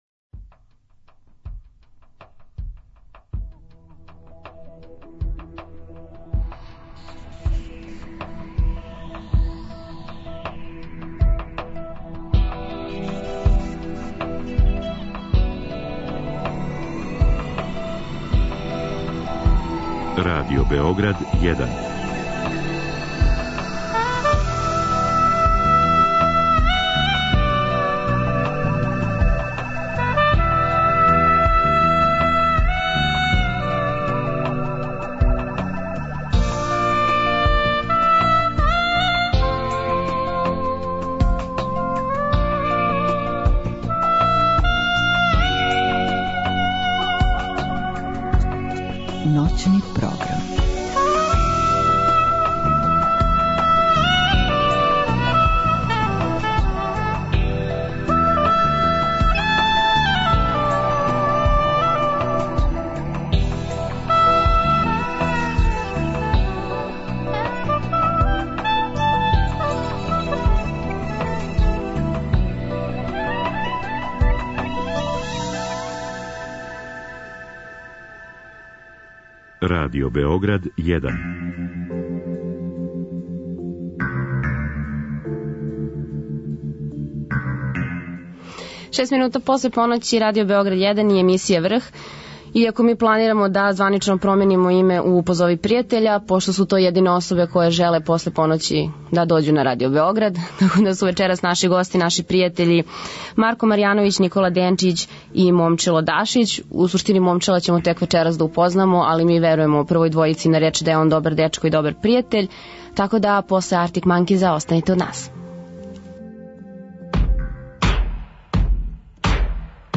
Сваке ноћи, од поноћи до четири ујутру, са слушаоцима ће бити водитељи и гости у студију